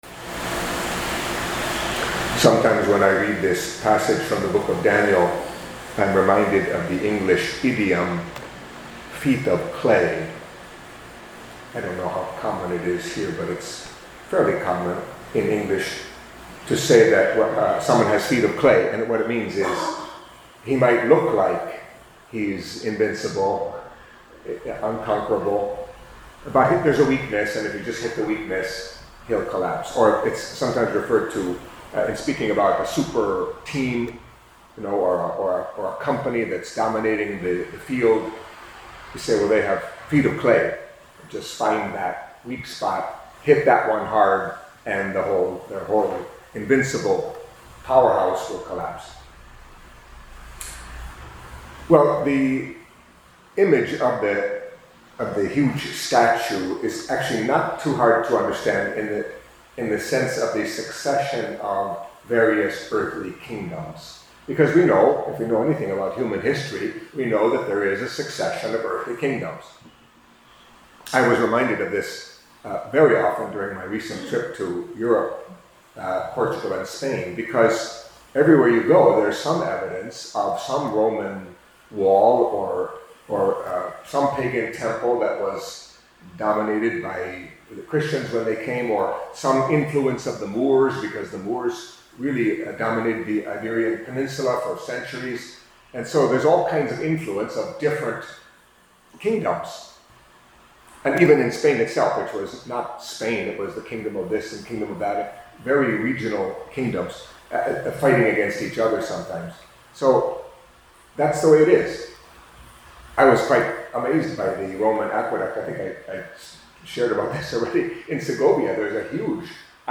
Catholic Mass homily for Tuesday of the Thirty-Fourth Week in Ordinary Time